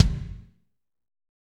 Index of /90_sSampleCDs/Northstar - Drumscapes Roland/DRM_Fast Rock/KIK_F_R Kicks x